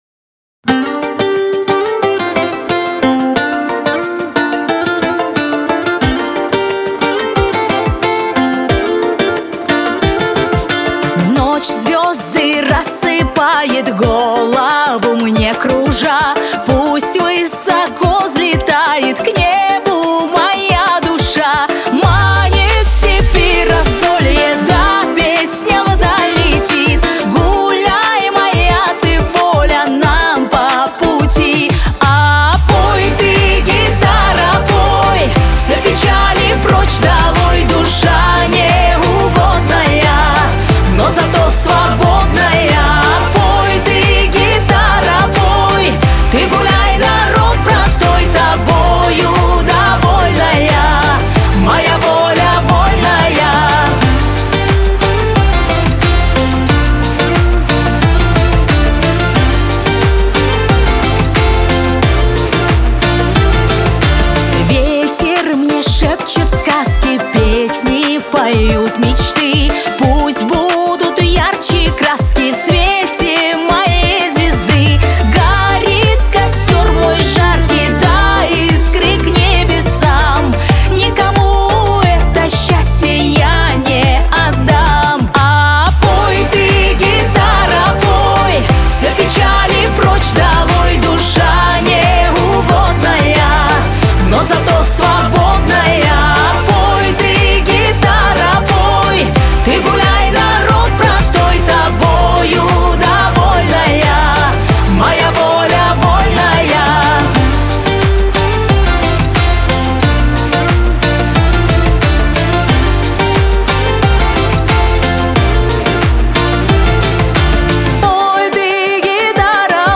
диско
эстрада